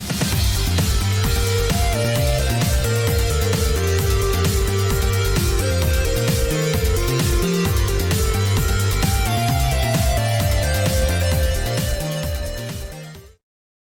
• Team Jingle